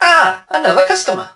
barley_hurt_04.ogg